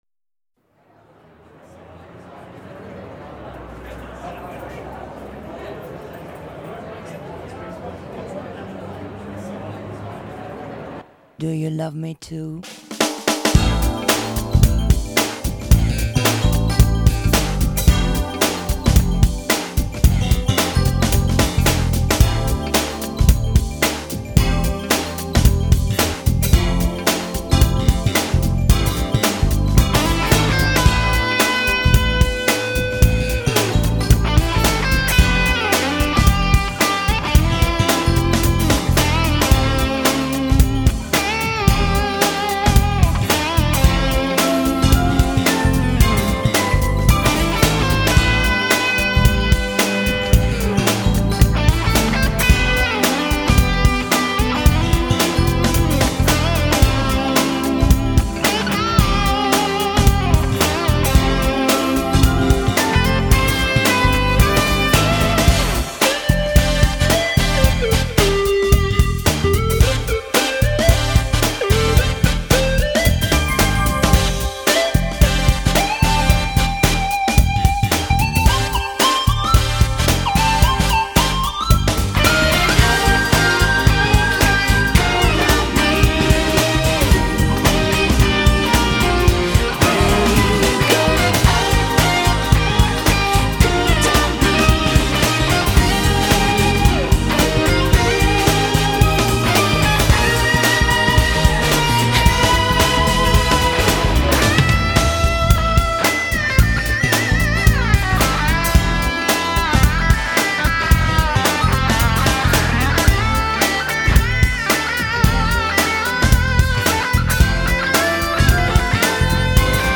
멜로디칼하고 좋더군요^^